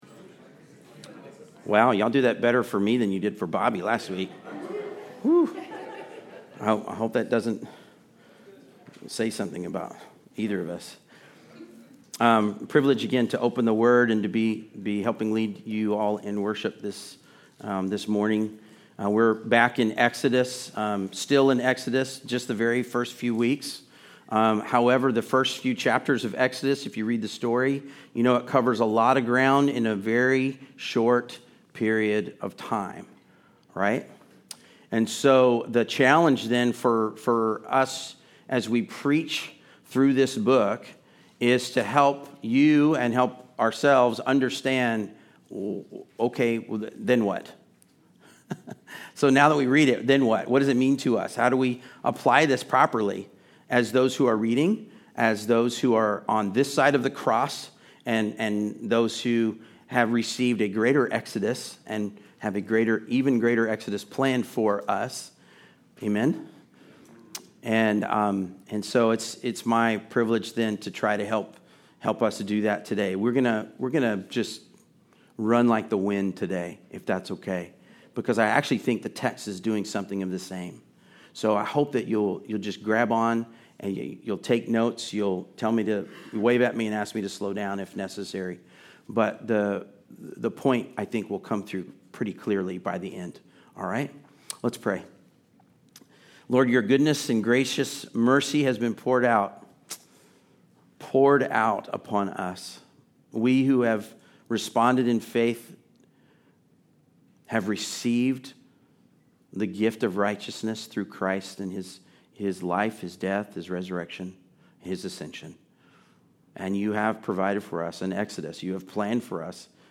Exodus 4 Service Type: Sunday Service Related « I AM God Is Always At Work!